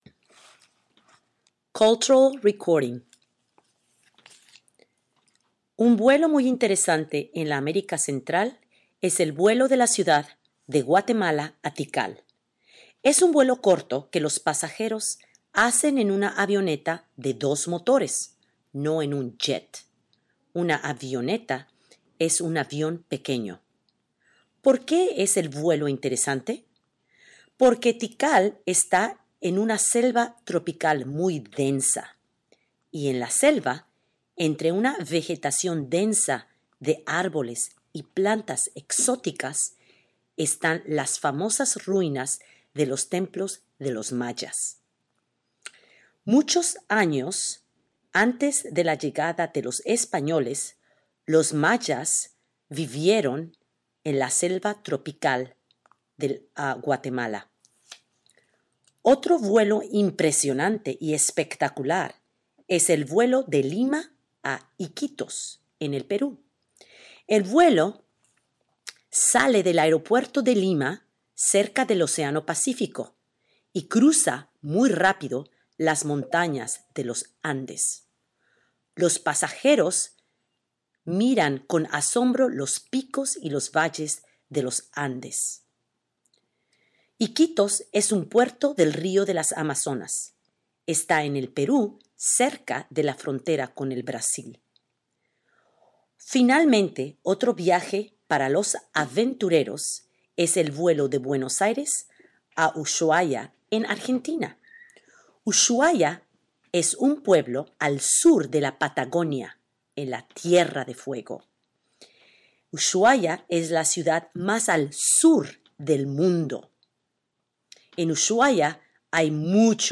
Short Spanish reading on 3 spectacular flights over LAtin America